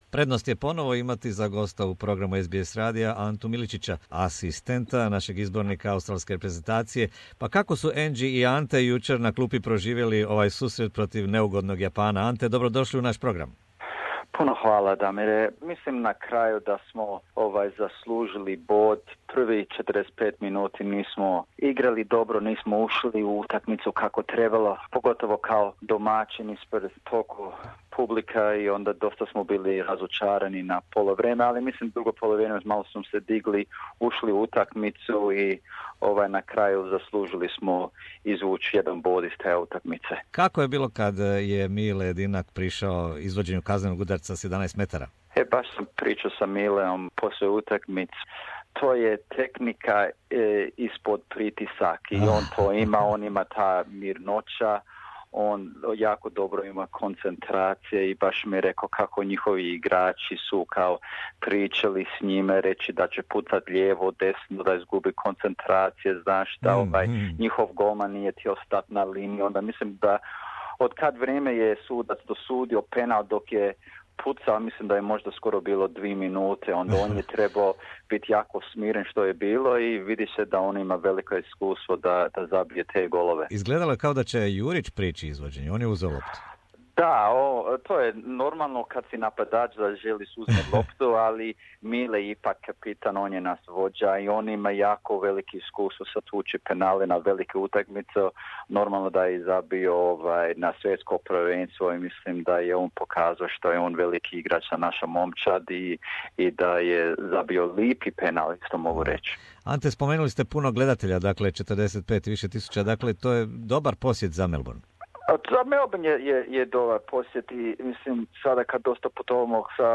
Pomoćni trener reprezentacije Australije Ante Miličić komentira podjelu bodova s Japanom (1:1) te nastup Hrvatske u njezinoj skupini za Svjetsko prvenstvo u Rusiji 2018. godine.